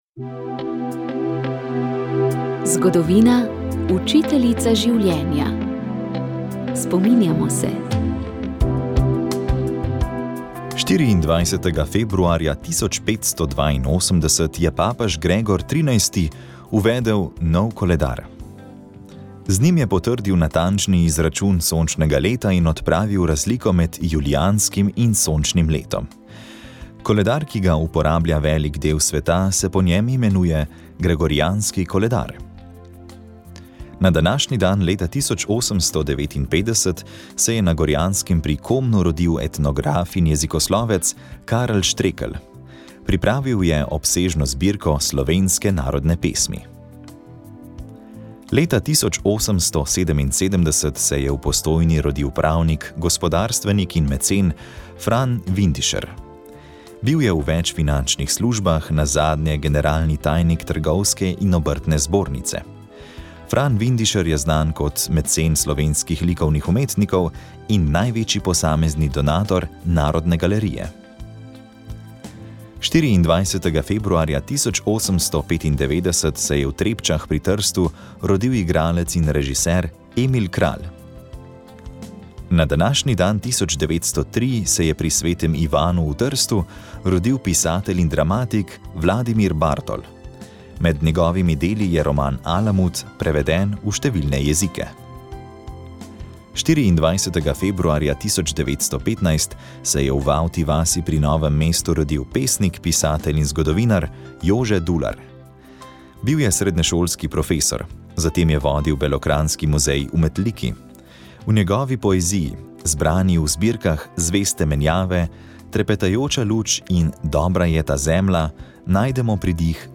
Informativni prispevki
pogovor